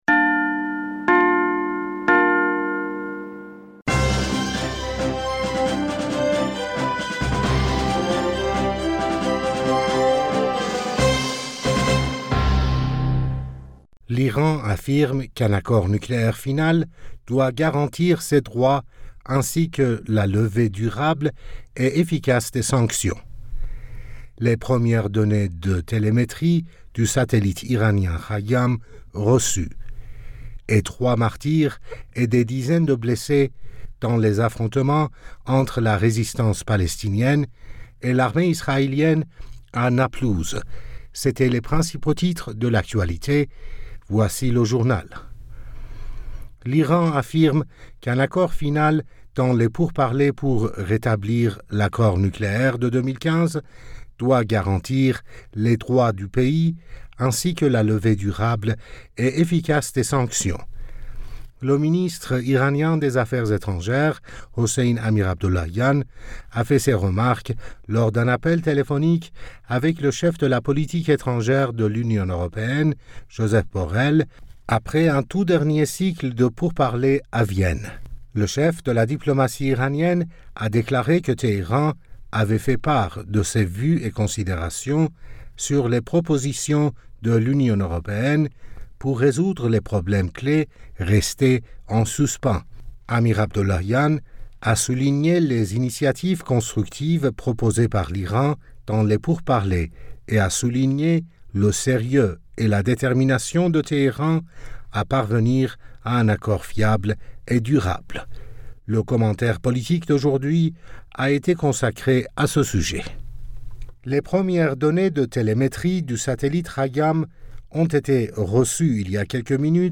Bulletin d'information Du 09 Aoùt